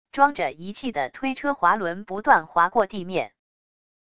装着仪器的推车滑轮不断划过地面wav音频